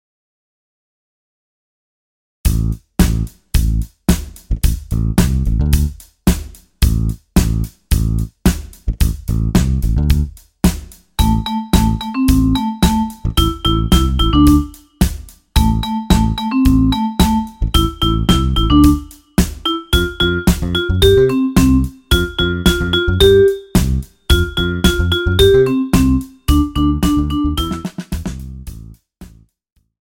Recueil pour Cor